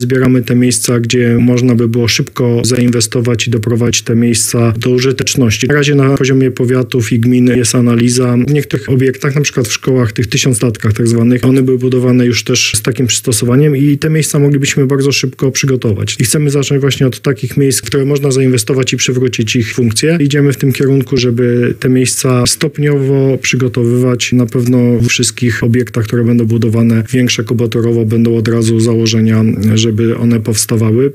– Zbieramy te miejsca, mamy nadzieję przedstawić je w najbliższych tygodniach – mówił w Radiu Lublin wicewojewoda lubelski Andrzej Maj.